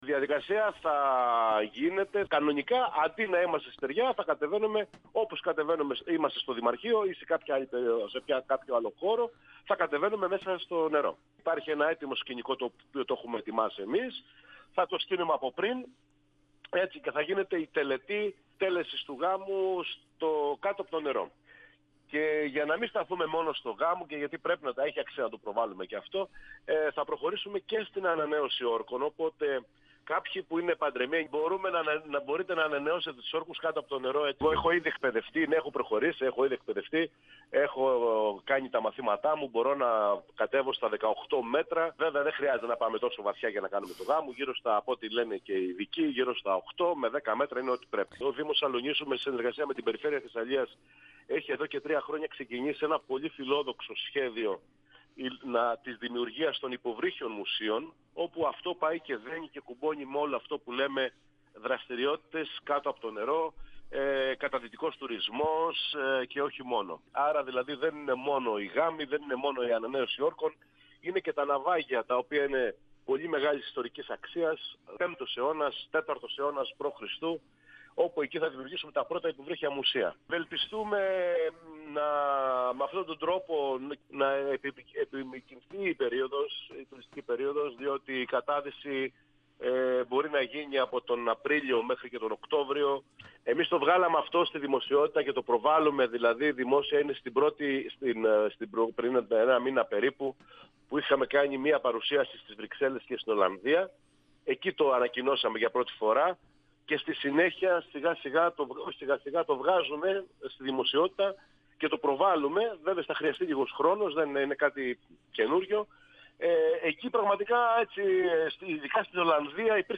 Ο δήμαρχος της Αλοννήσου, Πέτρος Βαφίνης, στον 102FM του Ρ.Σ.Μ. της ΕΡΤ3
Συνέντευξη